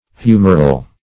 Humoral \Hu"mor*al\, a. [Cf. F. humoral.]